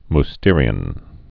(m-stîrē-ən)